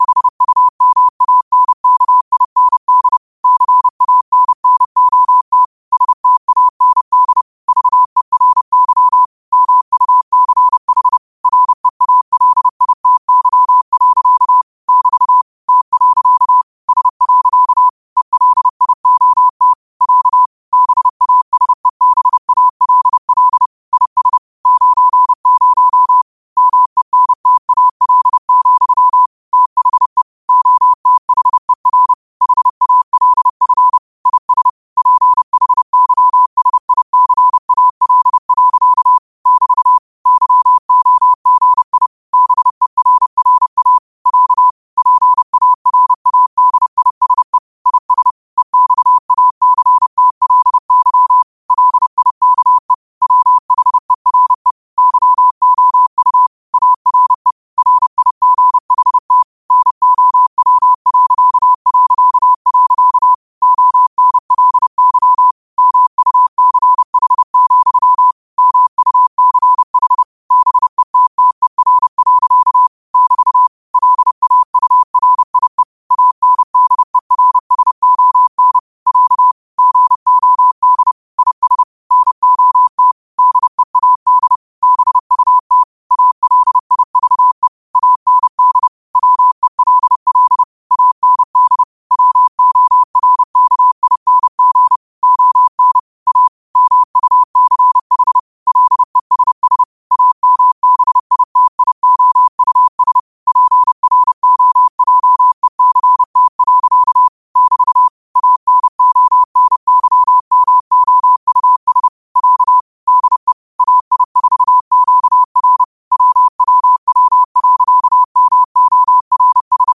Quotes for Thu, 14 Aug 2025 in Morse Code at 30 words per minute.